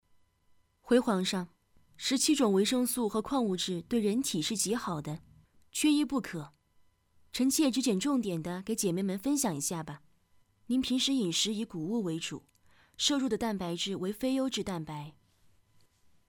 配音风格： 沉稳 大气 稳重 舒缓 轻快 浑厚 温情 激情 磁性
标签： 沉稳